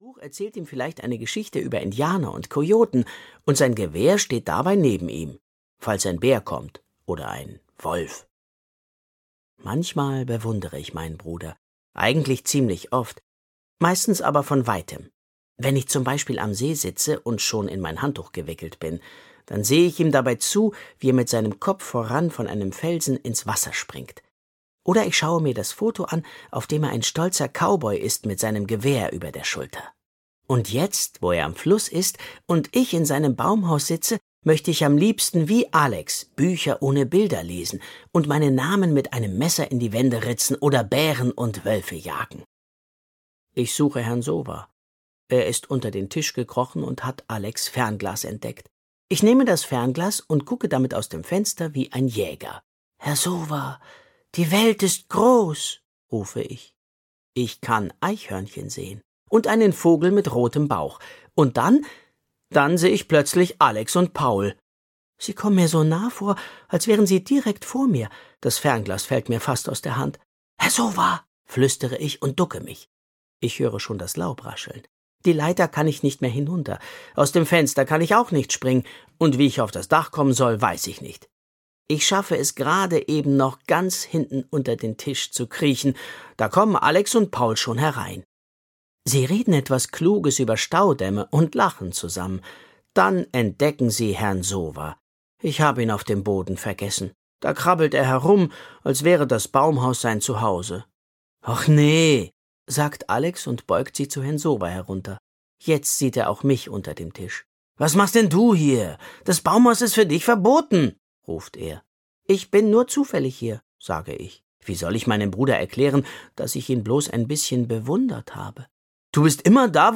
Hörbuch Ben 1: Ben., Oliver Scherz.